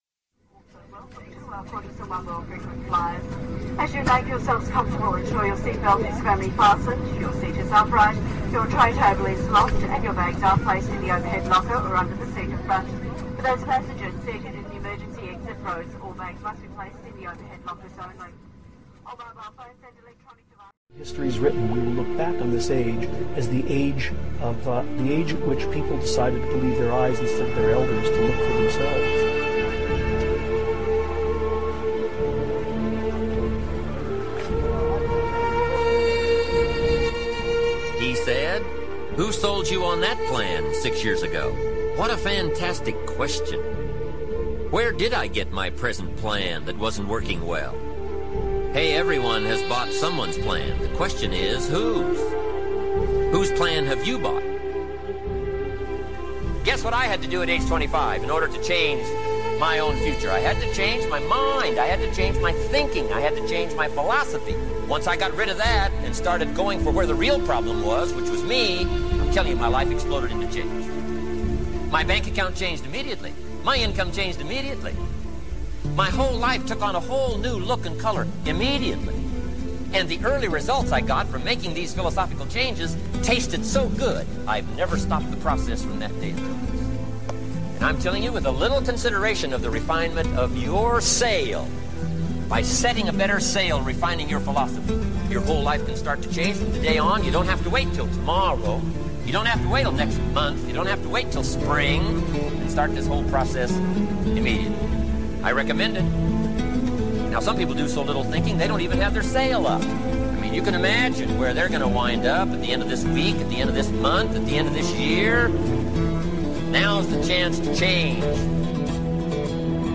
Many of the sounds, music and speech are mixed to create and associate feelings to certain things and activities.